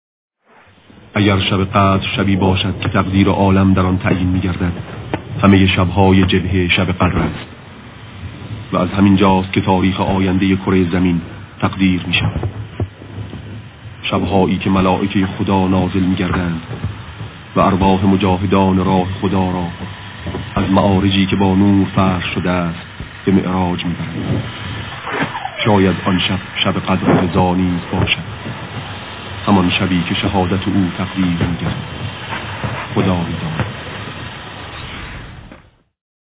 صدای شهید آوینی ؛ اگر شب قدر شبی باشد که تقدیر عالم در آن تعیین می گردد. همه شبهای جبهه شب قدر است و از همین جاست که تاریخ آینده کره زمین تقدیر می شود .